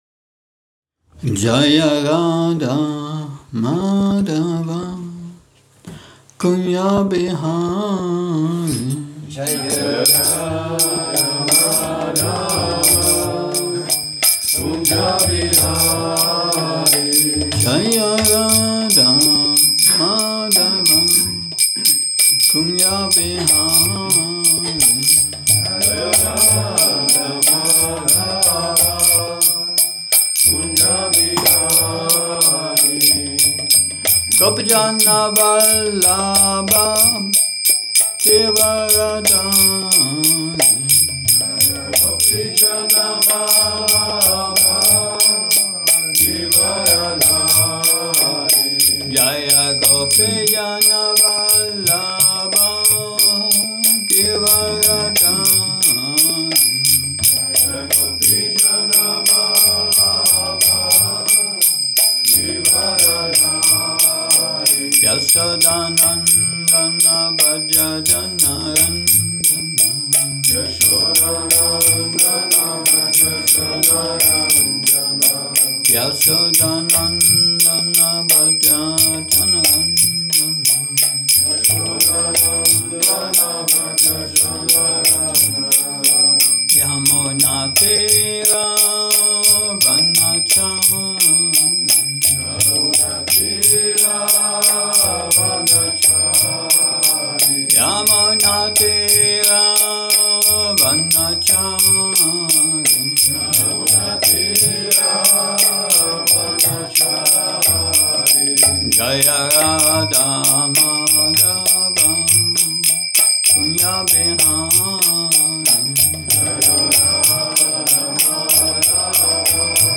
Šrí Šrí Nitái Navadvípačandra mandir
Přednáška SB-11.12.24